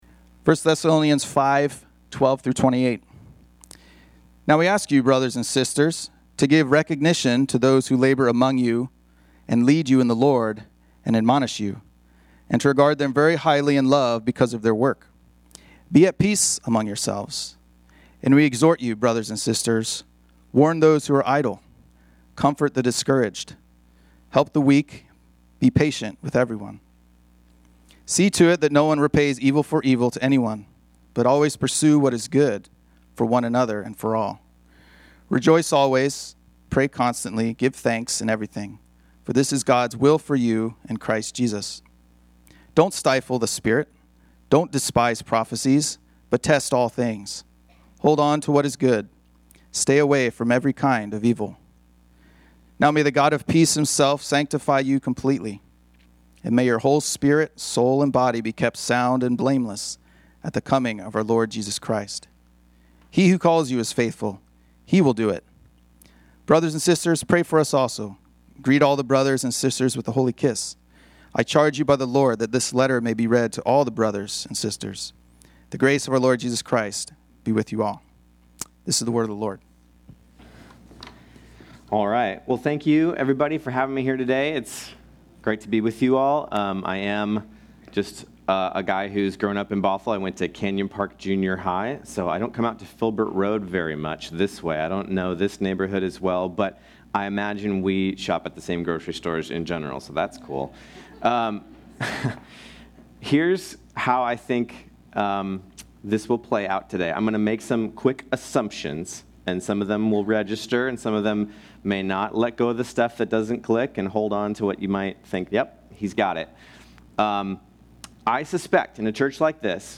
This sermon was originally preached on Sunday, August 17, 2025.